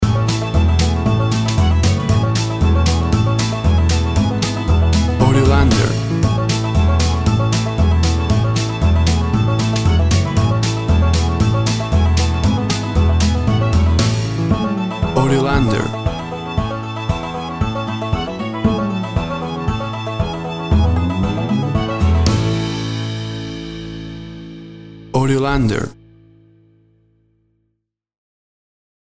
Classic country music sound.
Tempo (BPM) 115